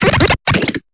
Cri de Larvibule dans Pokémon Épée et Bouclier.